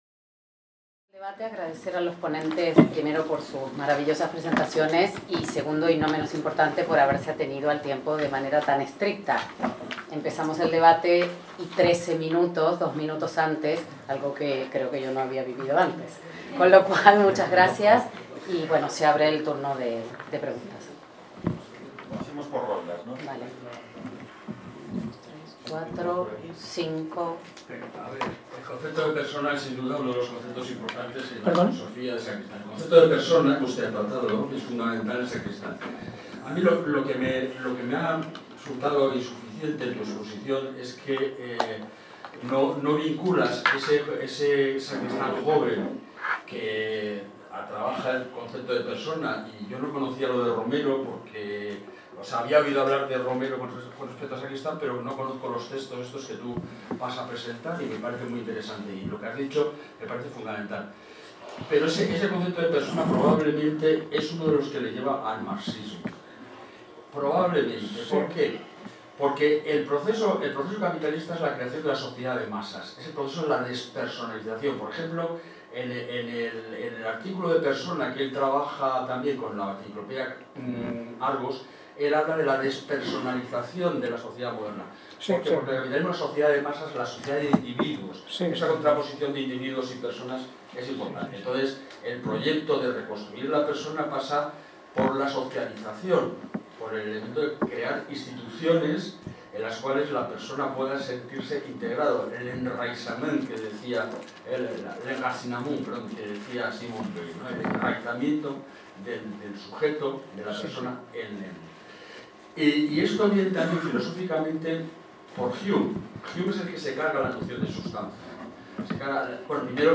Debat sessió 5
>  Cinquè debat que s'emmarca dins el Simposi Trias 2025, organitzat per la Càtedra Ferrater Mora, en col·laboració amb el Memorial Democràtic i dedicat al filòsof Manuel Sacristán.